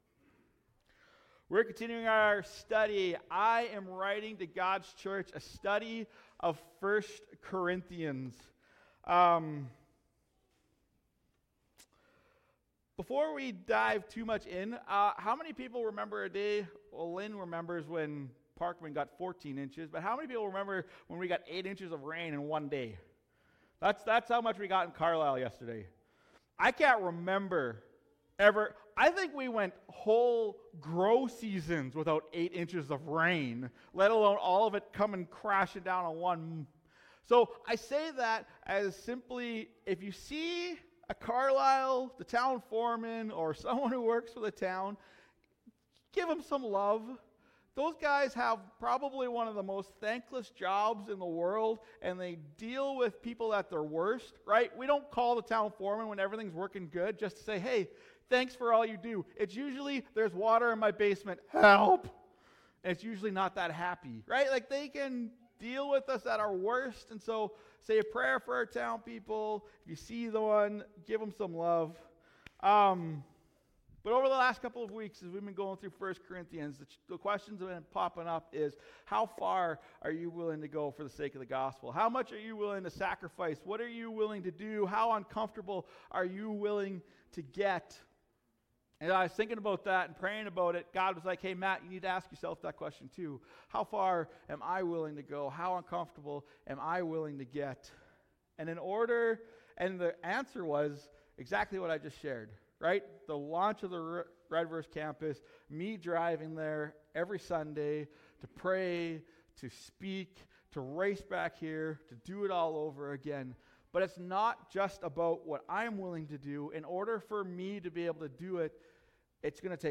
Sermons | OneChurch